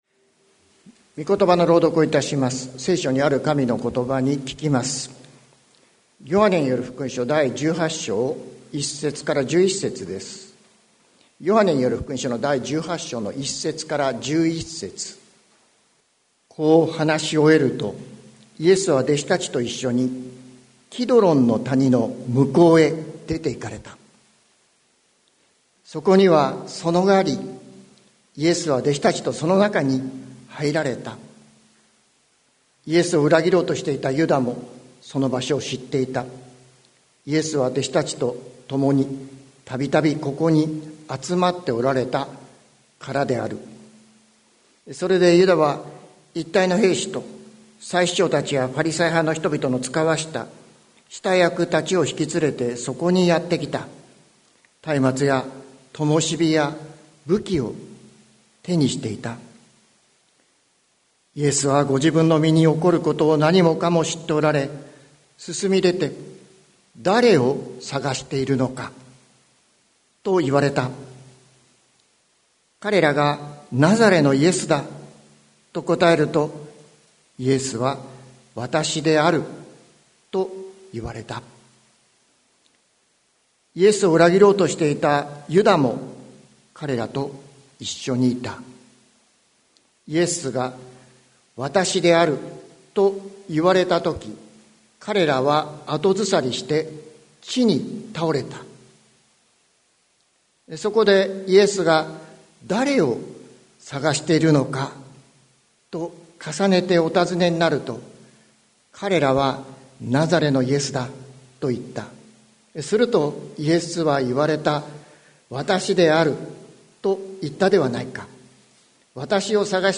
2023年10月08日朝の礼拝「だれを搜しているのか」関キリスト教会
説教アーカイブ。